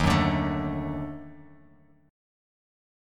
D#mM7b5 chord